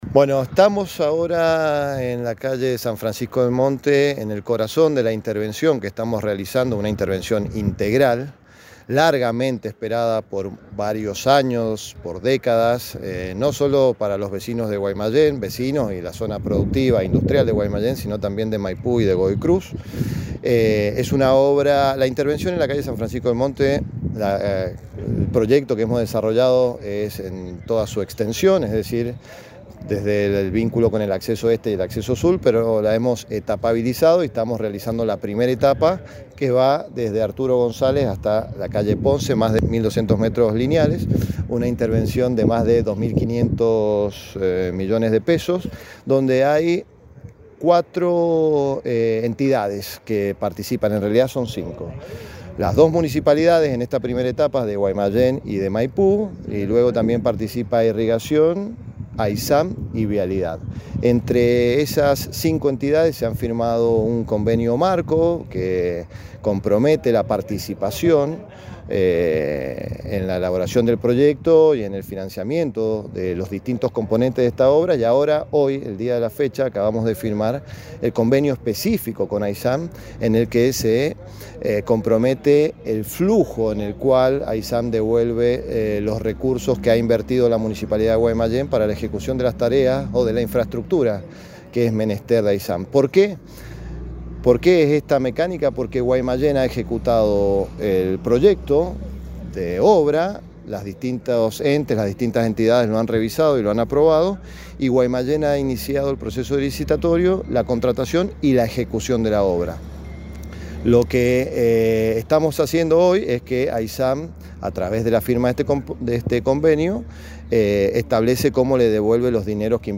Al referirse a la magnitud del proyecto sobre San Francisco del Monte, el intendente Calvente subrayó:
Marcos-Calvente-sobre-las-intervenciones-en-San-Francisco-del-Monte.mp3